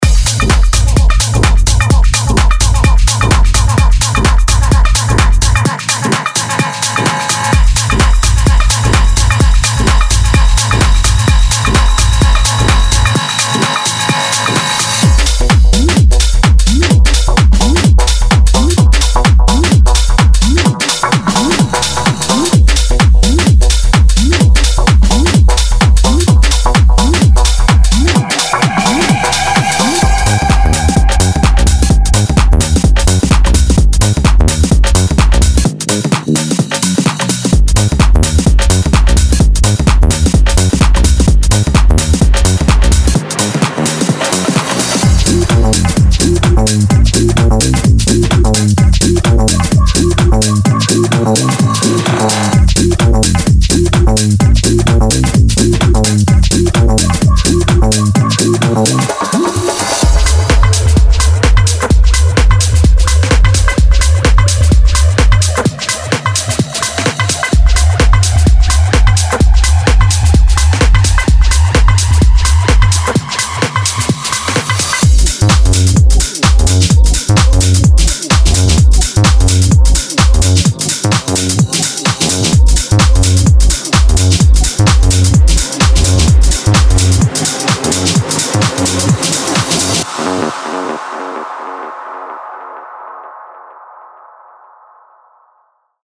The renowned French maestro delivers a powerful sample pack, built from the personal collection of loops and sounds that shaped his latest tracks.